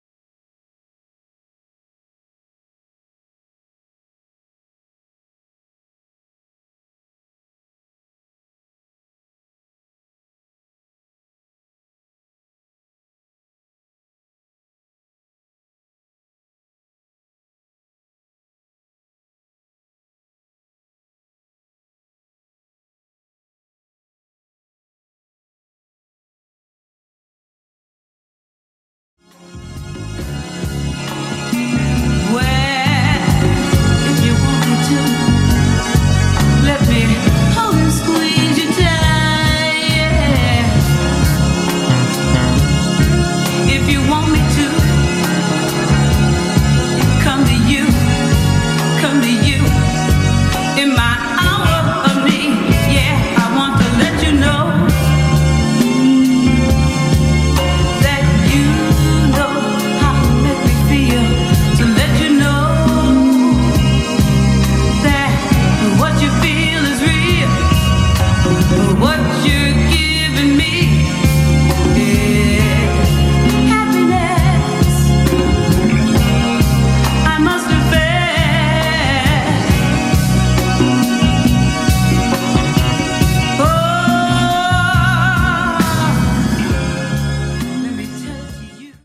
Dope disco-soul rarity on 45